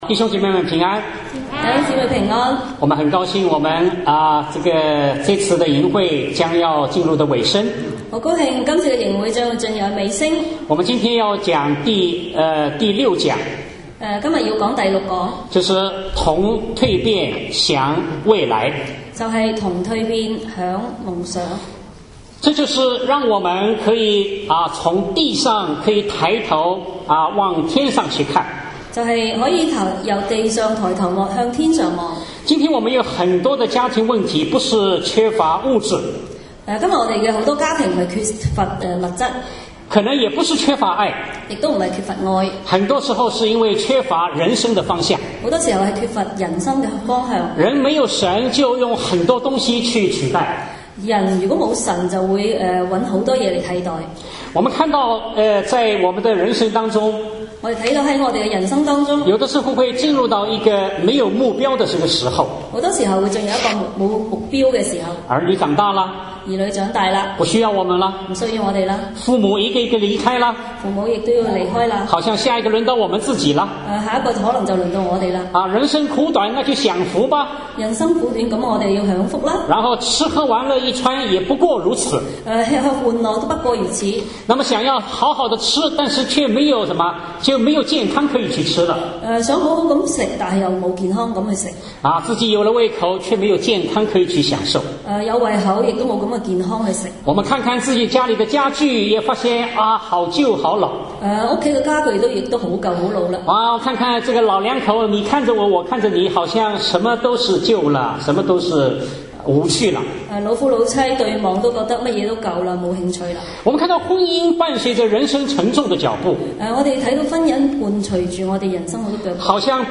主日讲道音频